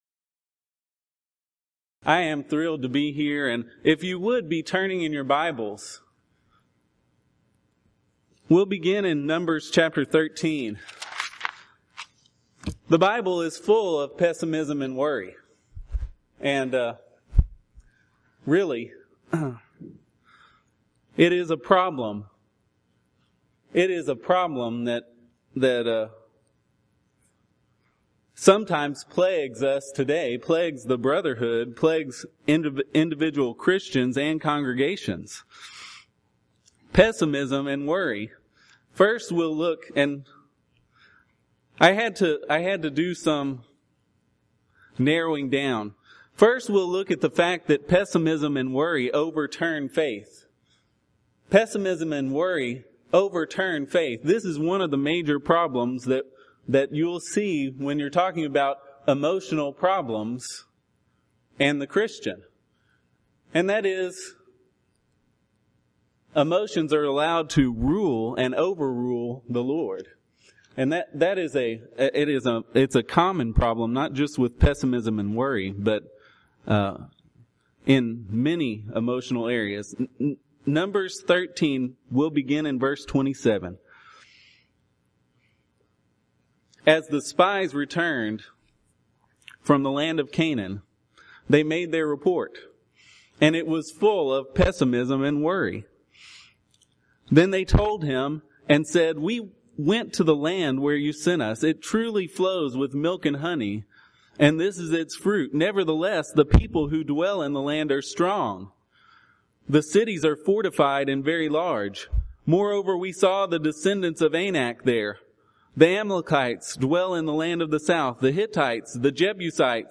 Event: 6th Annual Back to the Bible Lectures Theme/Title: Emotional Issues Facing the Church
this lecture